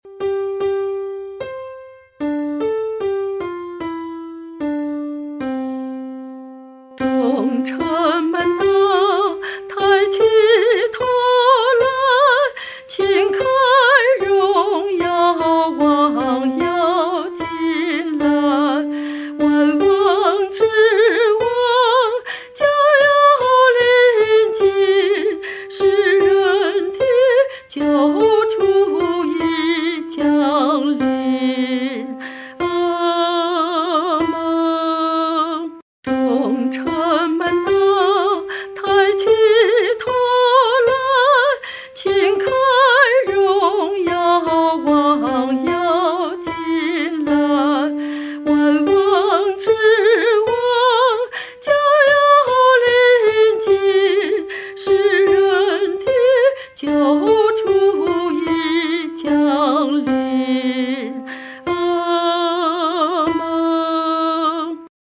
合唱
女高